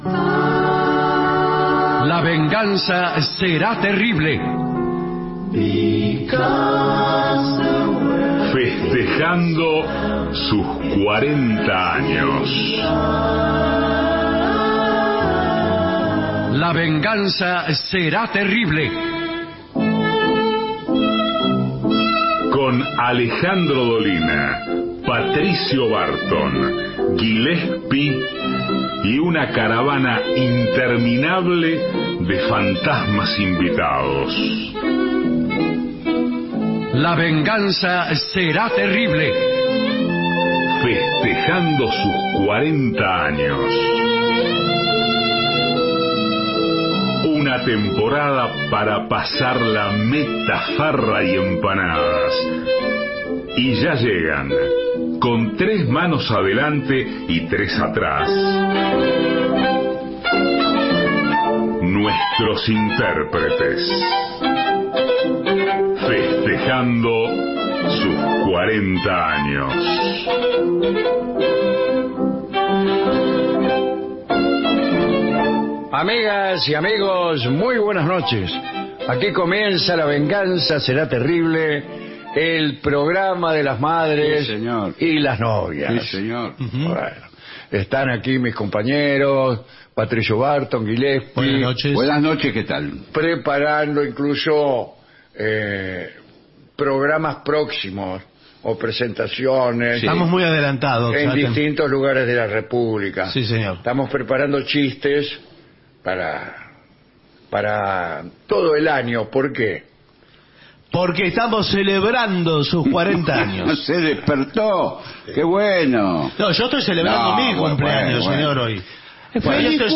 Estudios AM 750